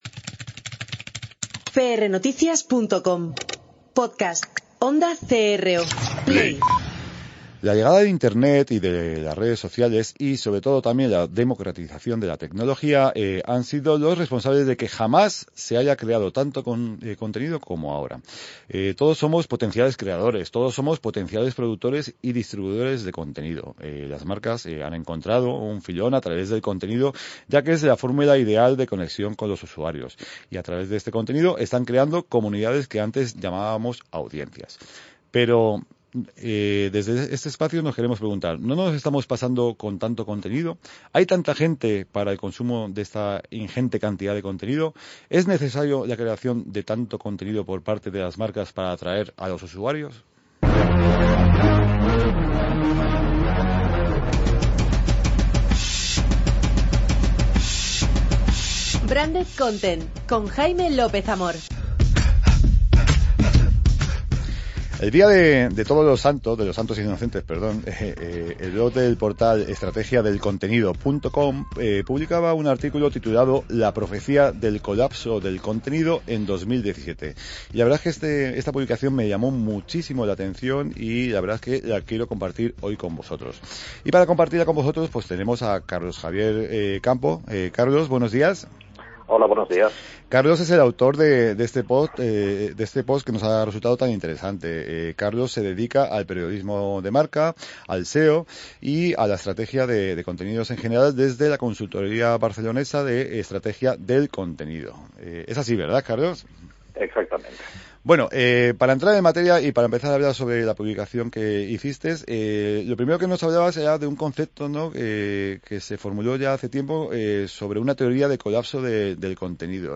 Por supuesto, en toda crisis, tenemos beneficiados y perjudicados que revelaremos en la entrevista así como qué alternativas tendremos para hacer frente a este colapso.